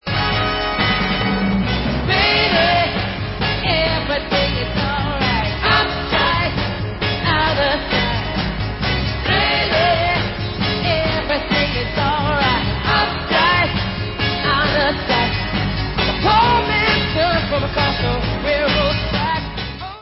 A COLLECTION OF FEEL-GOOD ANTHEMS OF SUMMER